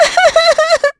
Estelle-Vox_Sad_a.wav